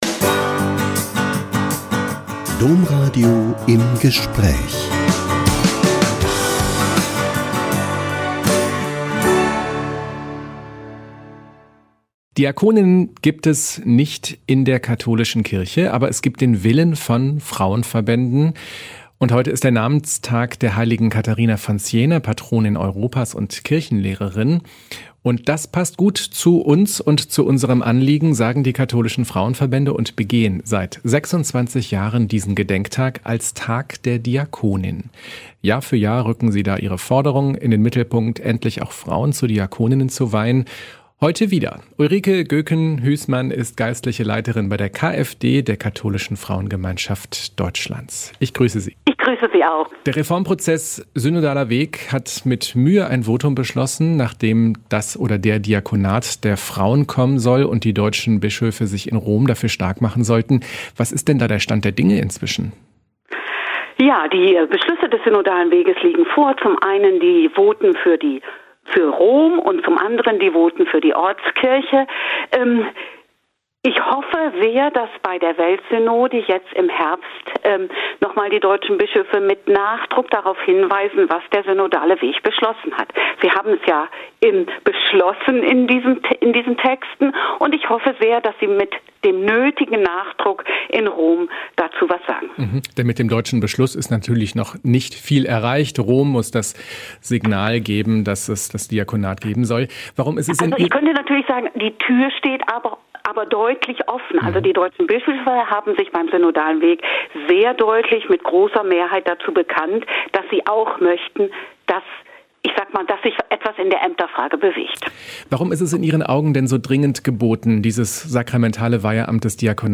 Ein Interview mit